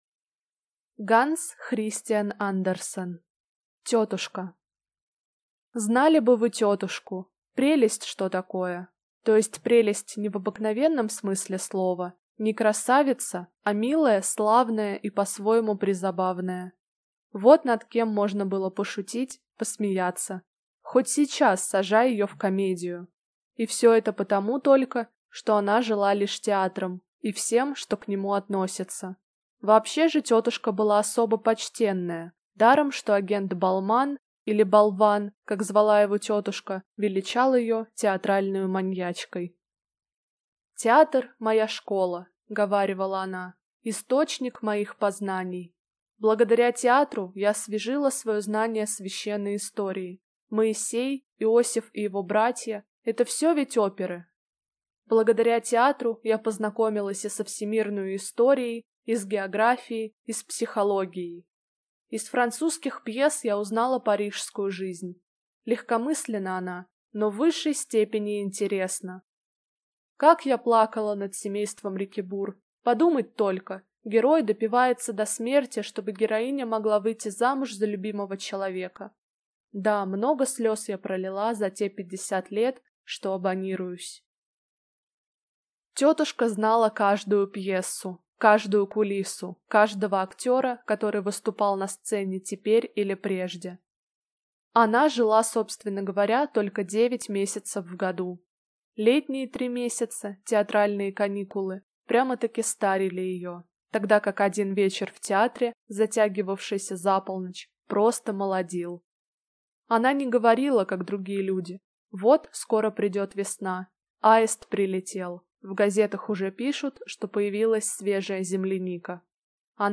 Аудиокнига Тётушка | Библиотека аудиокниг
Прослушать и бесплатно скачать фрагмент аудиокниги